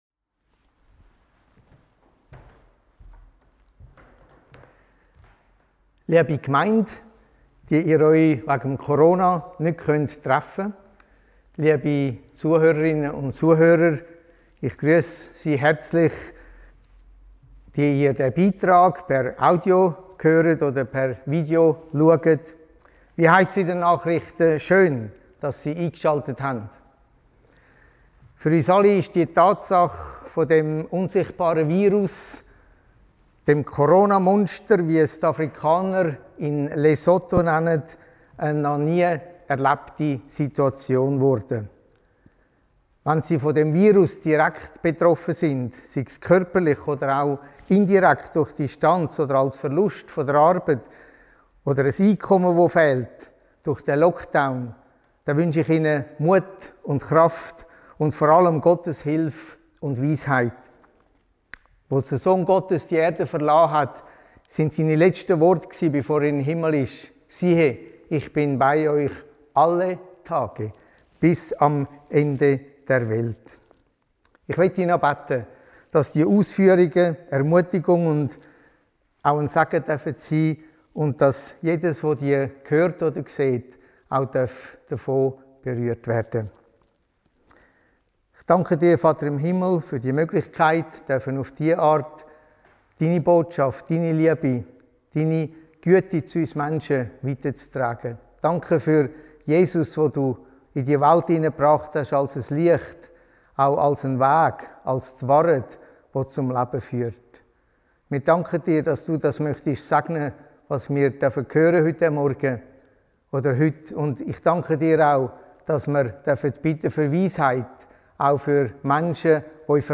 Missionsgottesdienst Lesotho